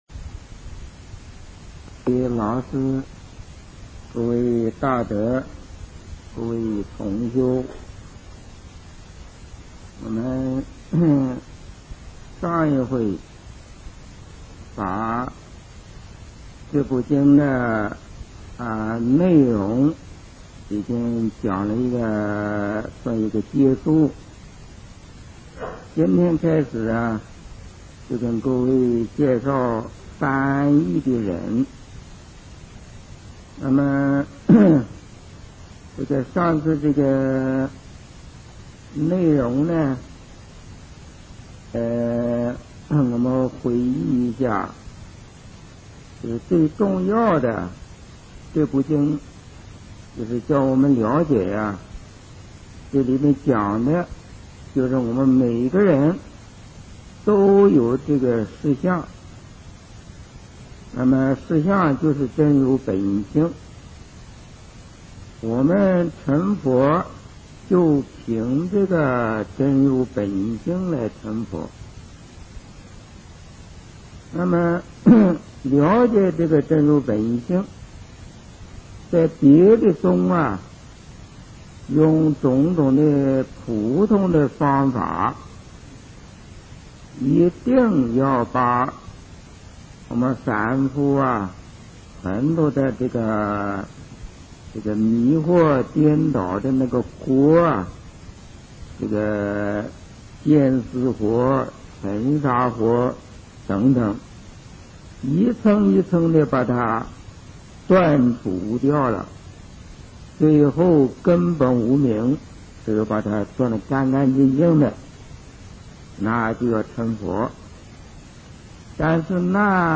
佛學講座-聲音檔